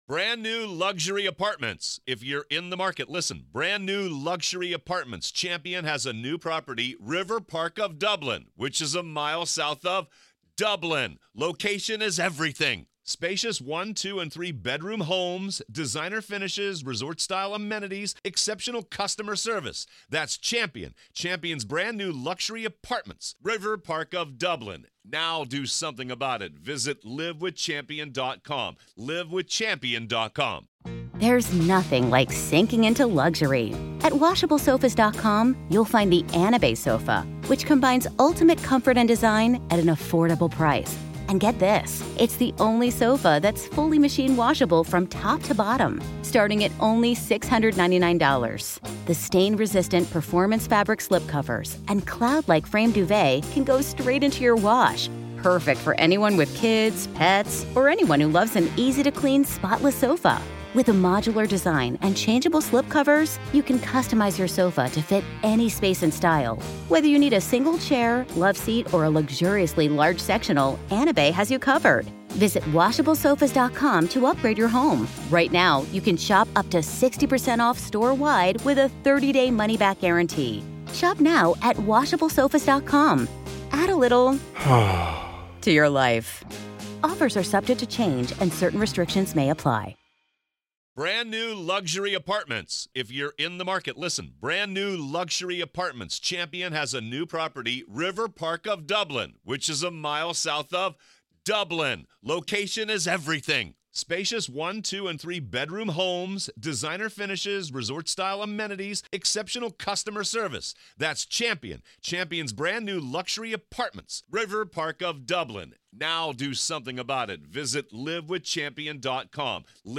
These powerful interviews provide unprecedented insight into the immediate chaos and confusion on that devastating day, revealing critical details never before shared publicly.